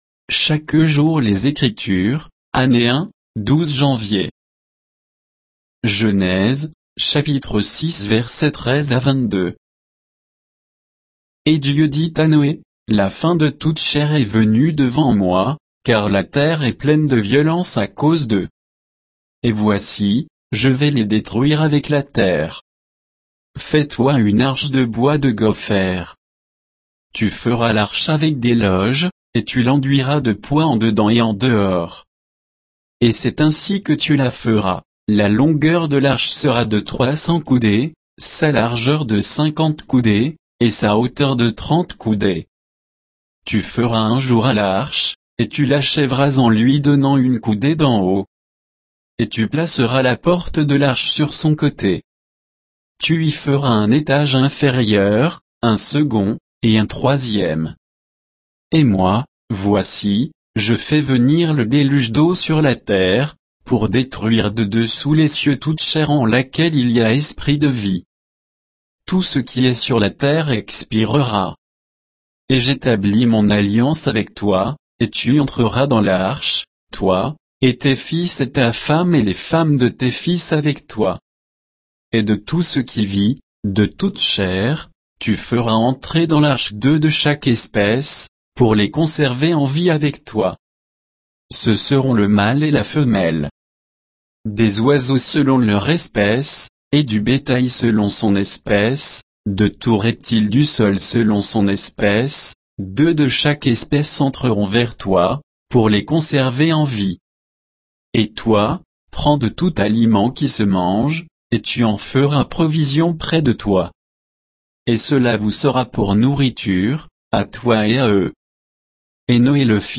Méditation quoditienne de Chaque jour les Écritures sur Genèse 6, 13 à 22